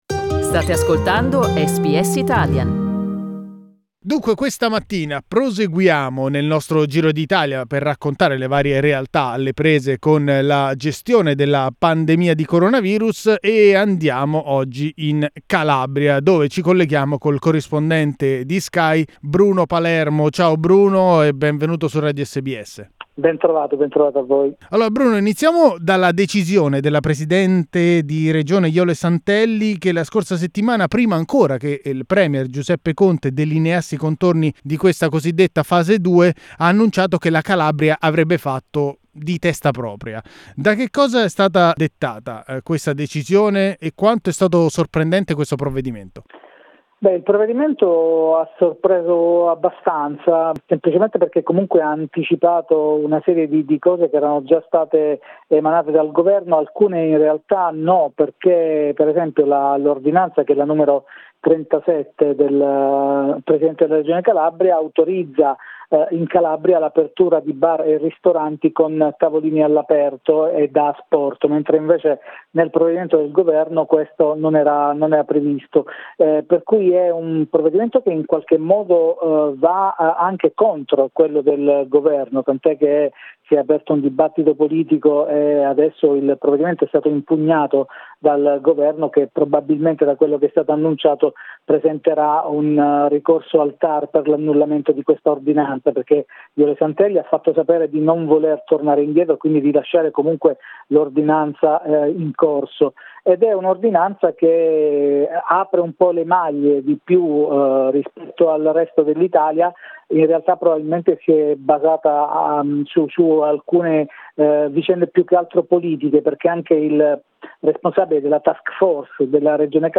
SKY correspondent from Crotone, reports.